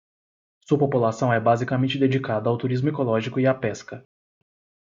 Pronounced as (IPA) /ˌba.zi.kaˈmẽ.t͡ʃi/